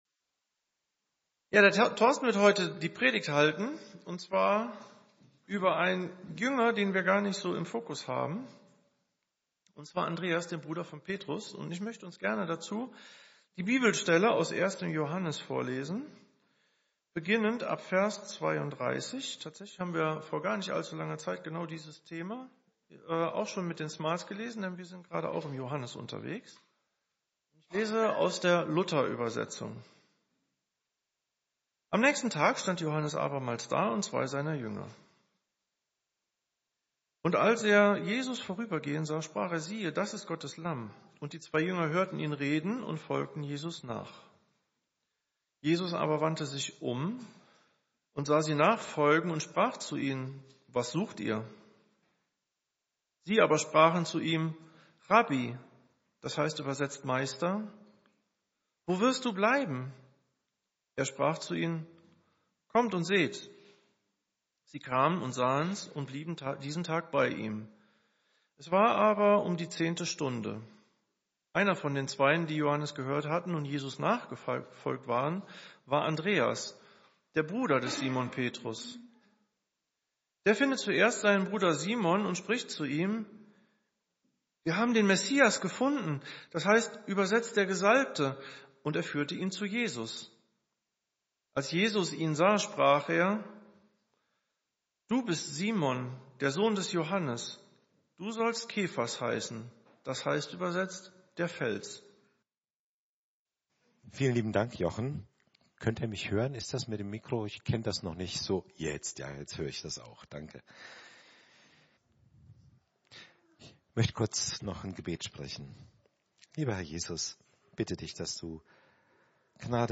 Gottesdienst - Evangelische Gemeinschaft Helmeroth